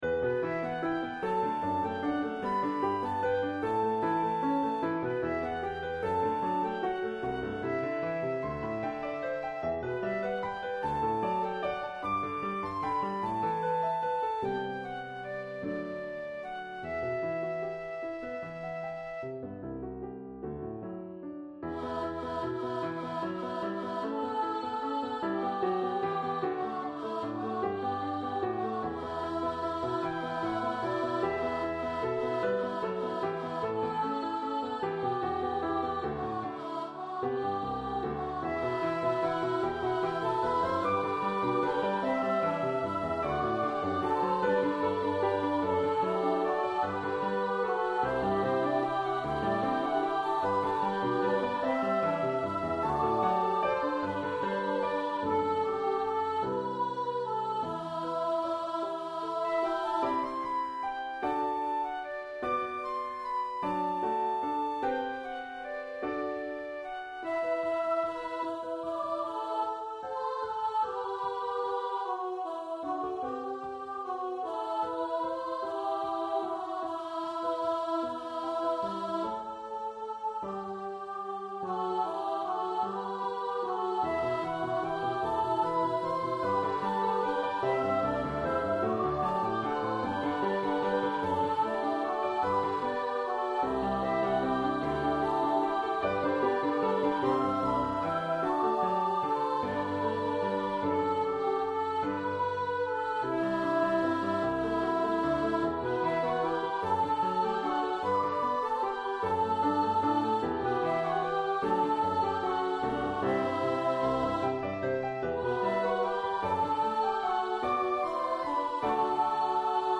Voicing: SA or TB, Flute and Piano